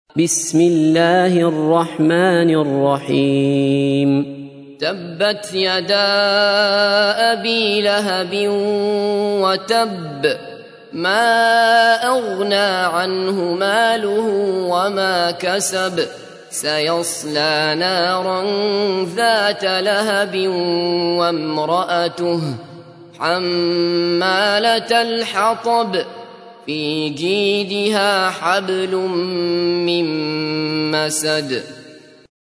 تحميل : 111. سورة المسد / القارئ عبد الله بصفر / القرآن الكريم / موقع يا حسين